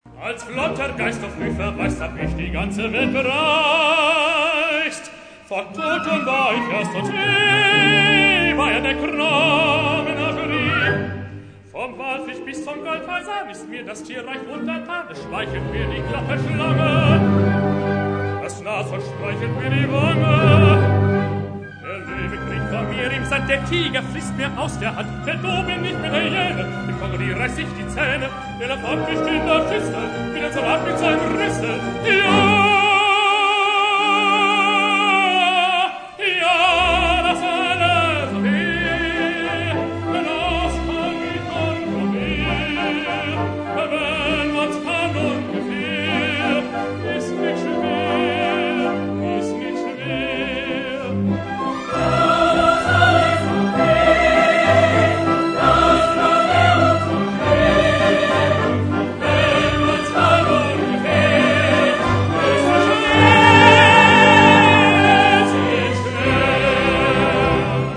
Populäre Arien aus Operette – Oper – MusicaL